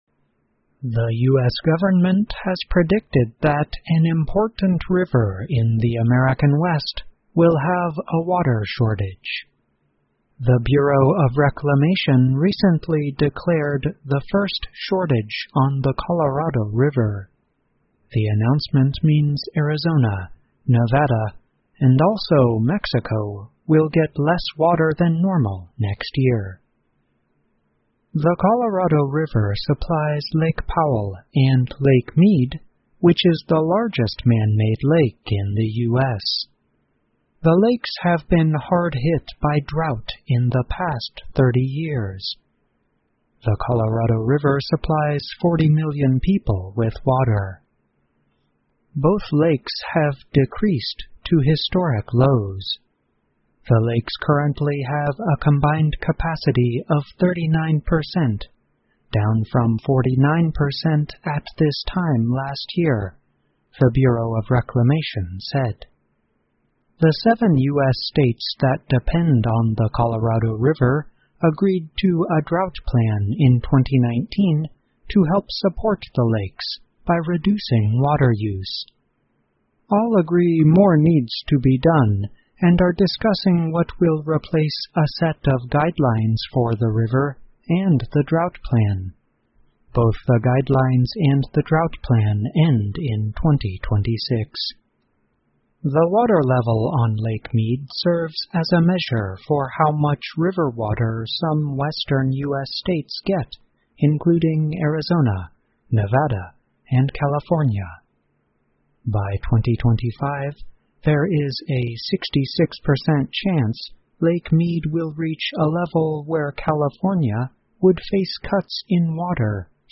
VOA慢速英语--缺少雨水给依赖科罗拉多河的州带来了压力 听力文件下载—在线英语听力室